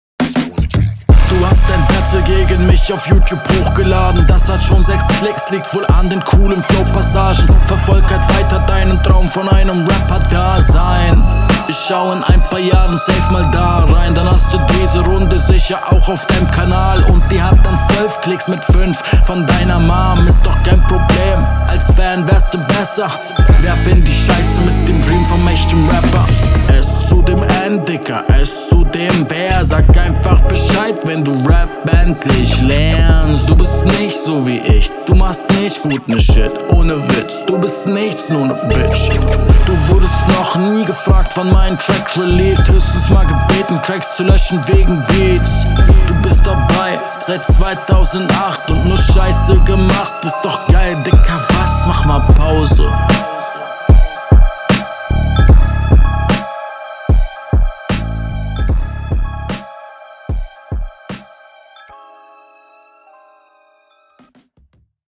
Schnelles Battle Format